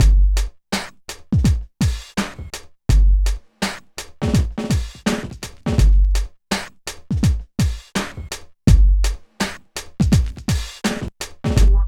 44 DRUM LP-R.wav